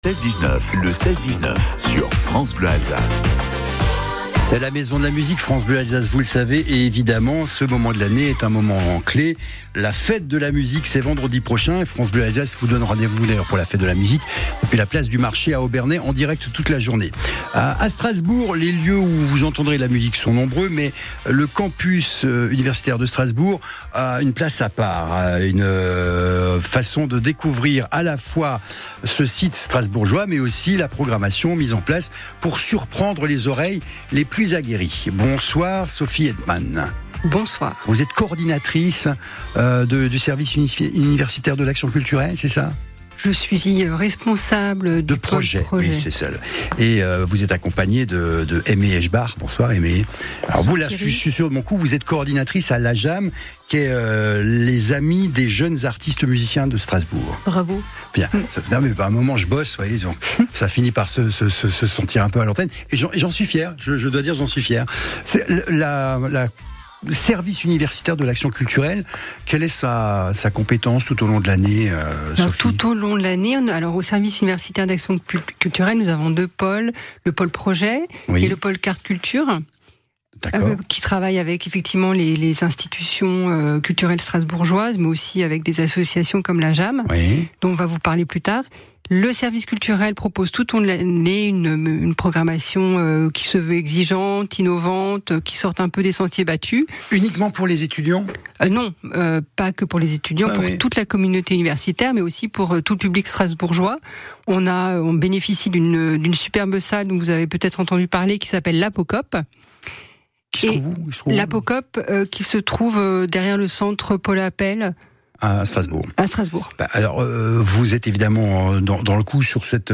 guitare Interview